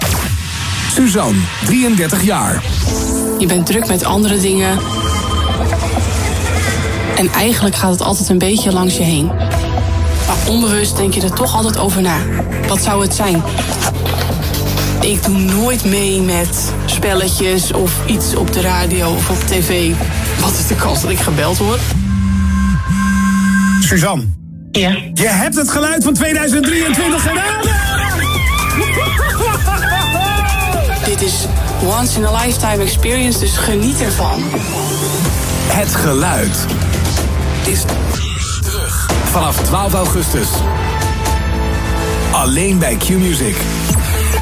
Vanaf maandag 12 augustus kan er weer geraden worden, zo blijkt uit een promo die Qmusic sinds een paar dagen uitzendt.
Qmusic-Het-Geluid-2024-promo.mp3